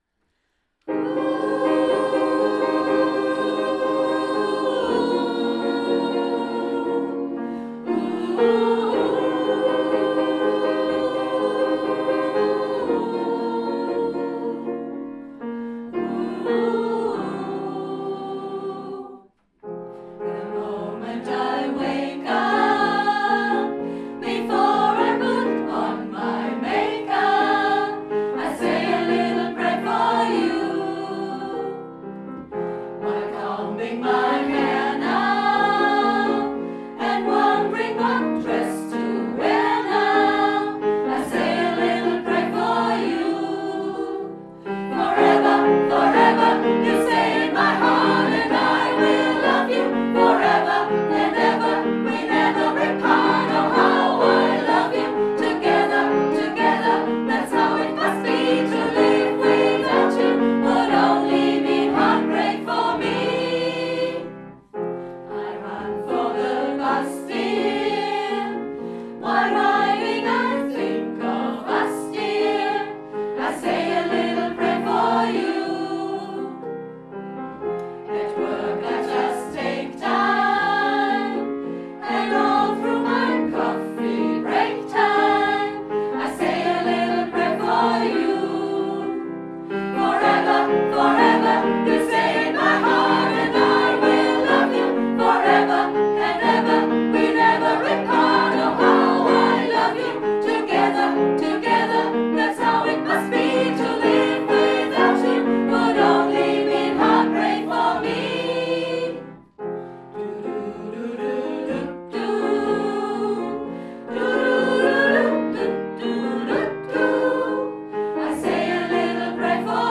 Der Frauenchor der Chrogemeinschaft besteht seit fast 50 Jahren und singt Lieder aus allen Zeiten und Genres.
Wir sind ein beständiger Chor, bestehend aus etwa 35 aktiven Sängerinnen zwischen 30 und 90 (!) Jahren, von denen einige schon seit 20, 30 und 40 Jahren zusammen singen.